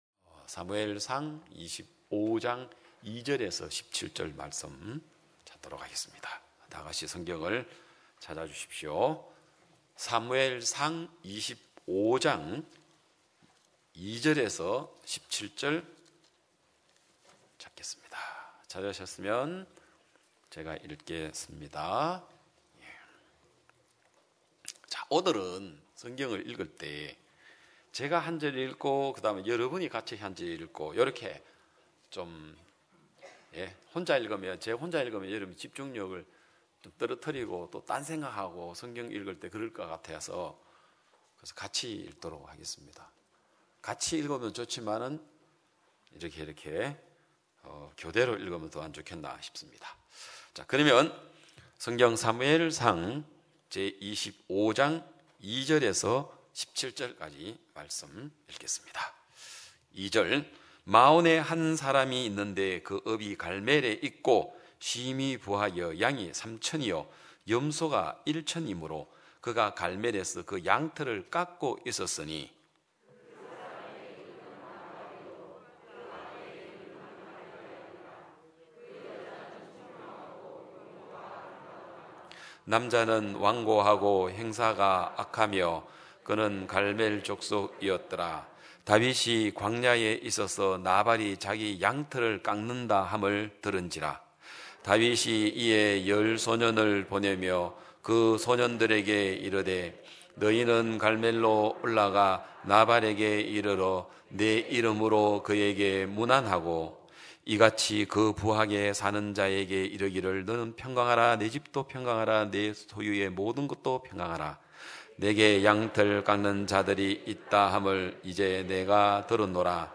2022년 5월 1일 기쁜소식양천교회 주일오전예배
성도들이 모두 교회에 모여 말씀을 듣는 주일 예배의 설교는, 한 주간 우리 마음을 채웠던 생각을 내려두고 하나님의 말씀으로 가득 채우는 시간입니다.